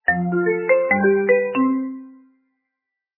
completed_tone_low_br.mp3